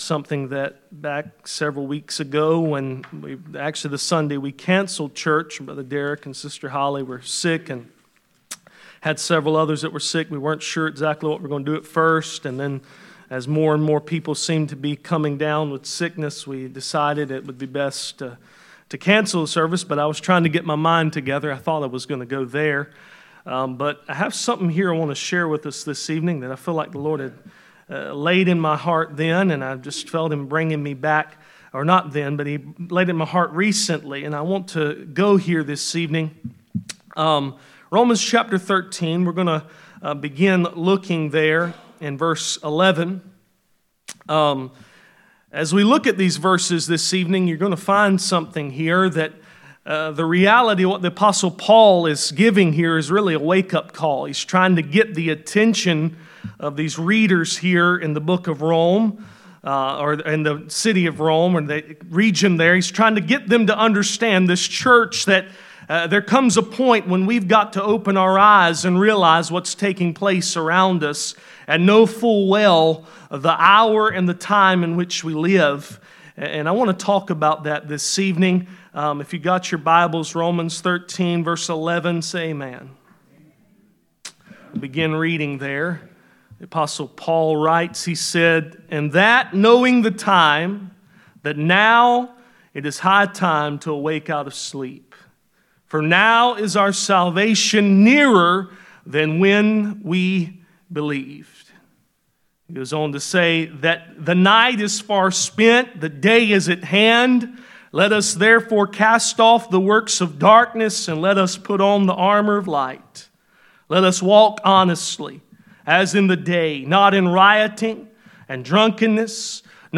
None Passage: Romans 13:11-14 Service Type: Sunday Evening %todo_render% « When your harvest field becomes your battlefield Hope